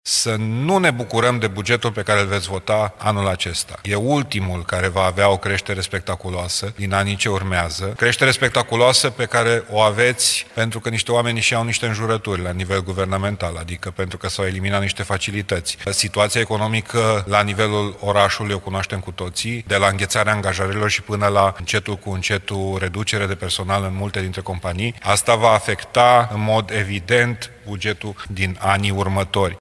În contextul discuțiilor despre necesitatea creditului, consilierul liberal Dan Diaconu, fost viceprimar al orașului, a lansat un avertisment.